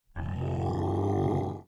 oso.wav